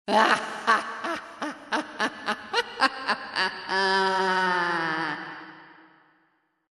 Звуки ведьмы
Зловещий хохот колдуньи